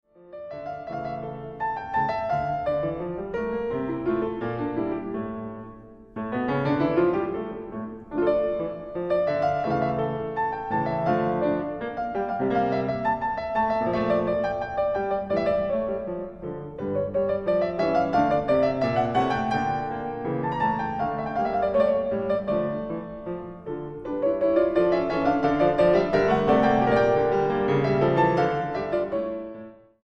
transcripción piano